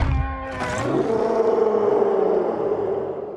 File:Catcher roar.mp3
Catcher_roar.mp3